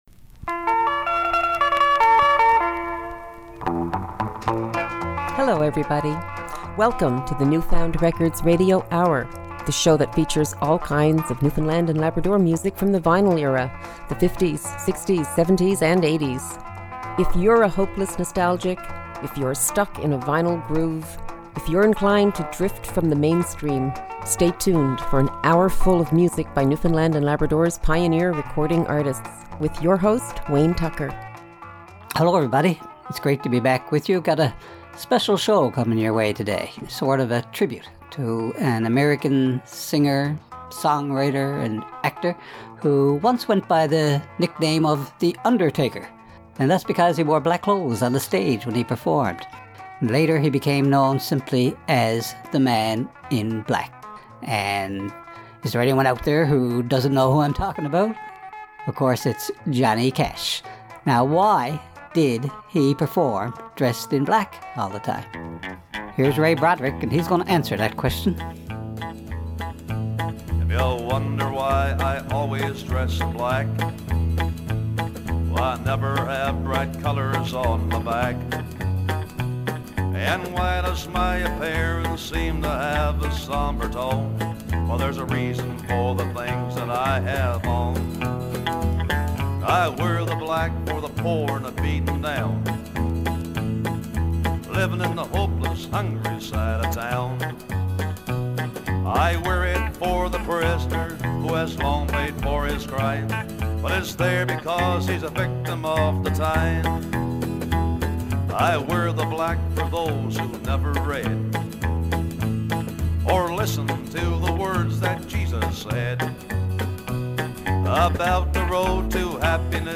Vinyl records by Newfoundland & Labrador's pioneer recording artists.